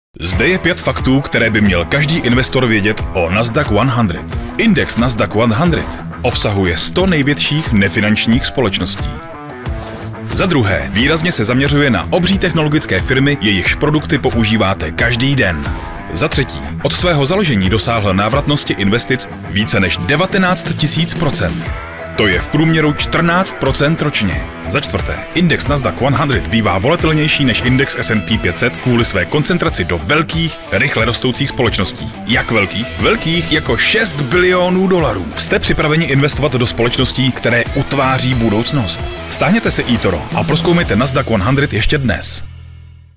Součástí každého jobu je i základní postprodukce, tedy odstranění nádechů, filtrování nežádoucích frekvencí a ekvalizace a nastavení exportu minimálně 48kHz/24bit, okolo -6dB, jestli se nedohodneme jinak.
Mužský voiceover - hlas do krátkých reklamních spotů!